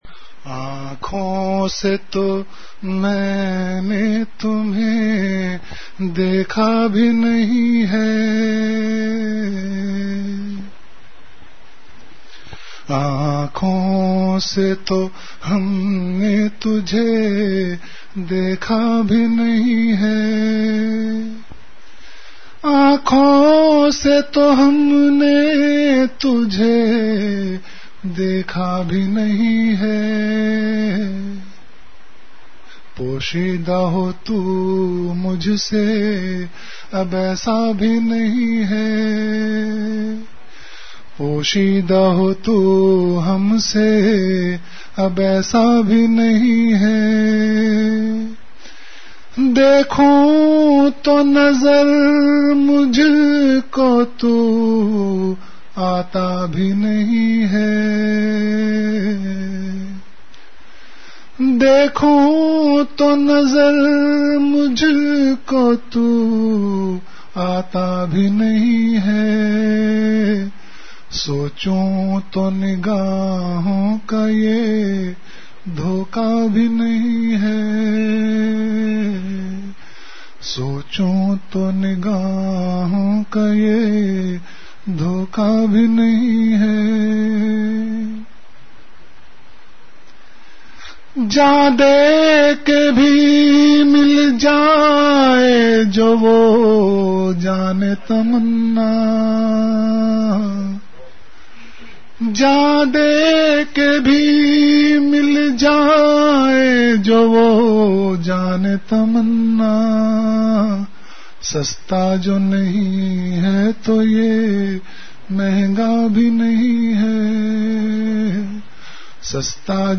Delivered at Home.
Majlis-e-Zikr · Home Tauba o Istaghfaar Ka Sahara Aur Allah(SWT
Event / Time After Isha Prayer